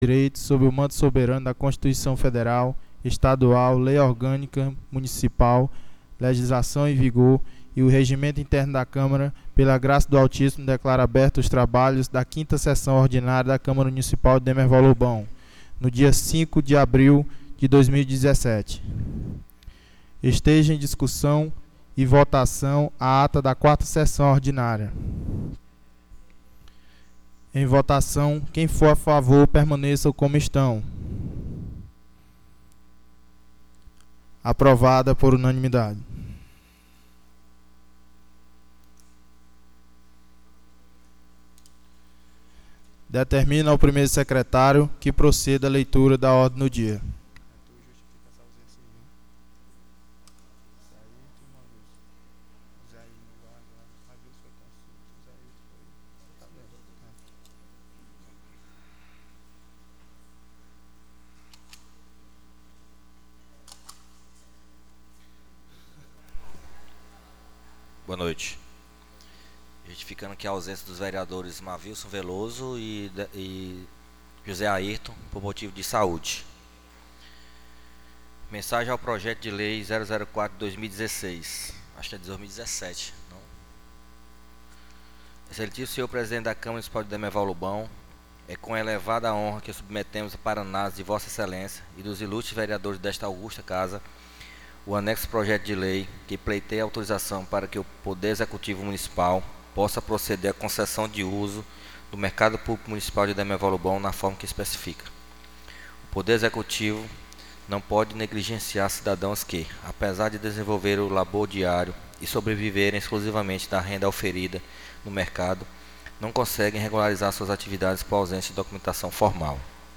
5ª SESSÃO ORDINÁRIA 05/04/2017